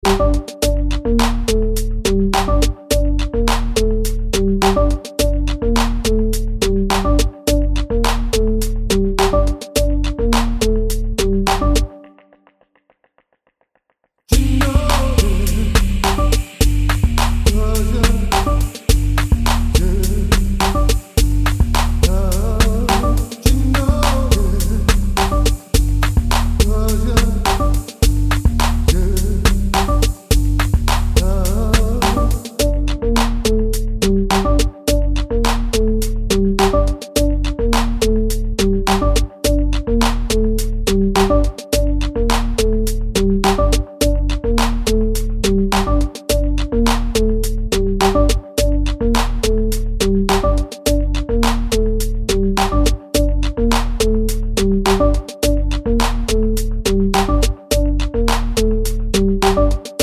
no sample BV R'n'B / Hip Hop 3:38 Buy £1.50